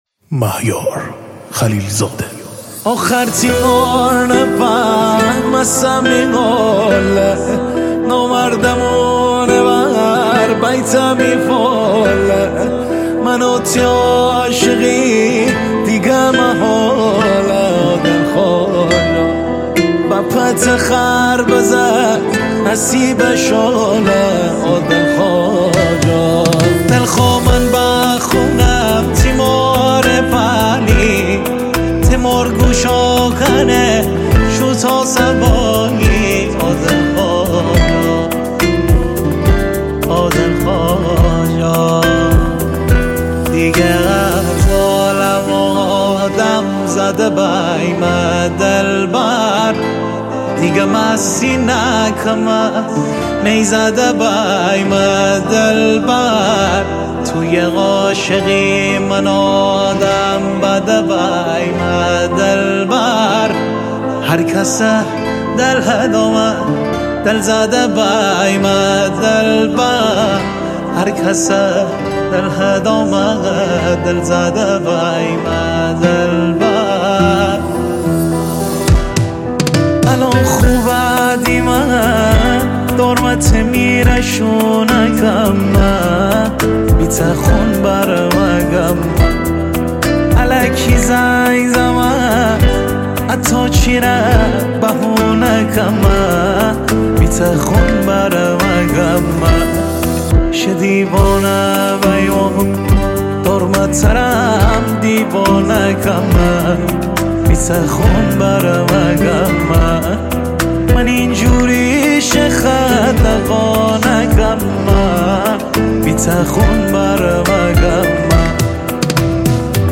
سبک ریمیکس مازندرانی